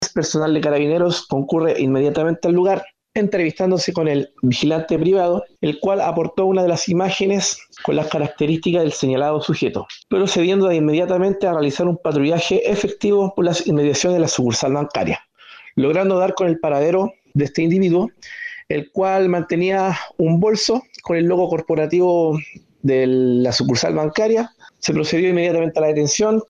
cu-robo-banco-carabinero.mp3